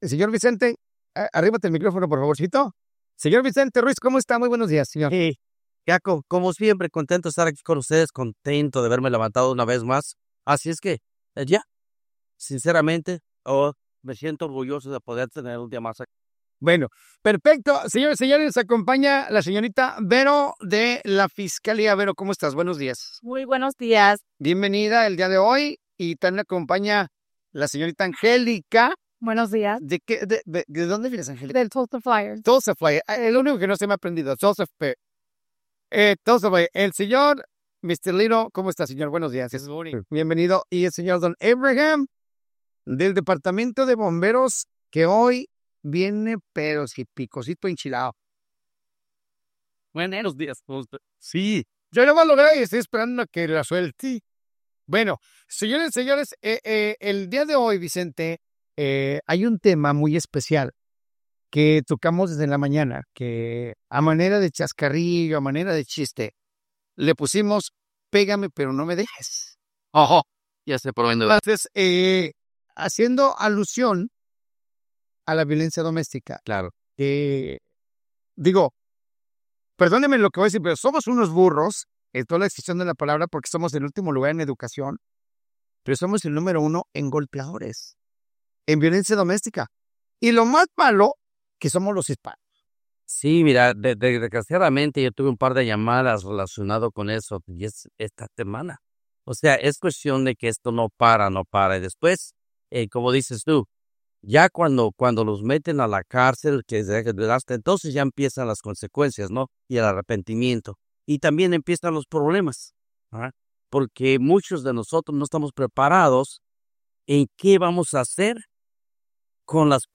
En el programa de hoy, los oficiales compartieron recomendaciones cruciales sobre la violencia doméstica, recordando que evitarla comienza con controlar las discusiones, buscar ayuda profesional, mantener la calma, retirarse del lugar antes de perder el control y nunca responder con agresión.